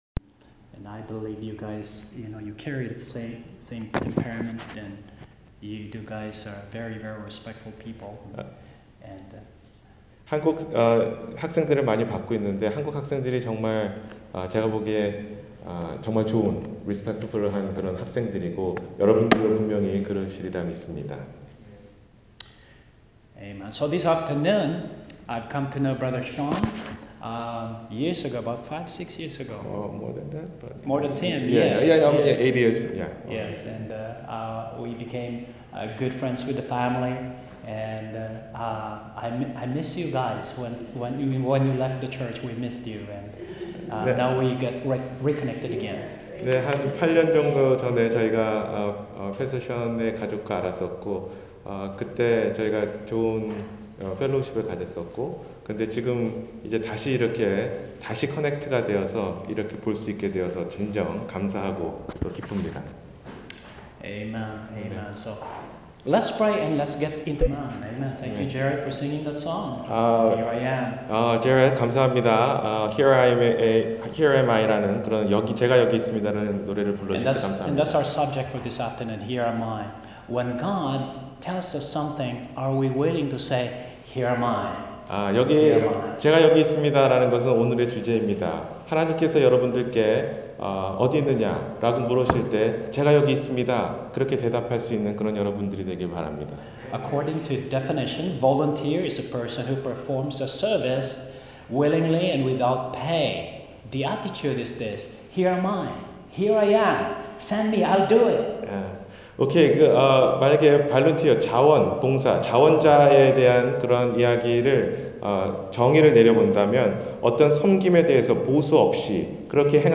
제가 여기 있나이다 – 주일설교